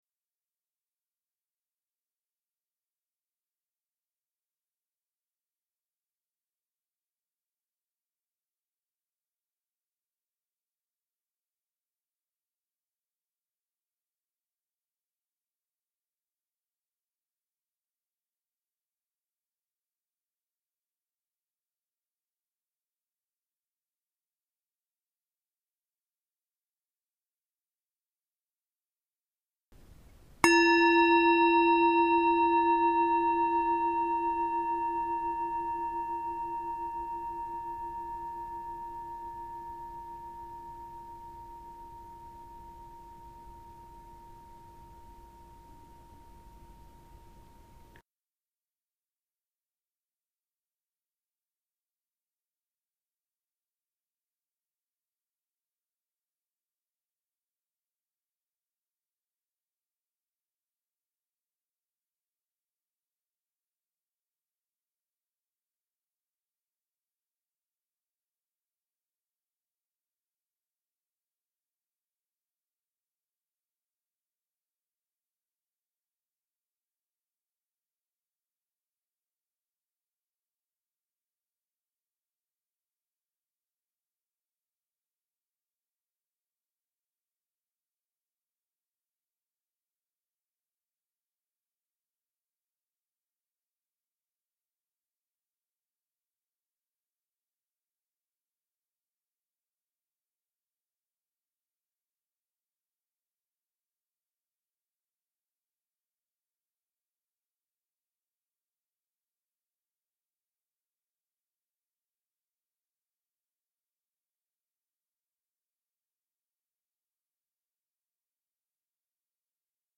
Stille Meditation 10 min
stille-meditation-10-min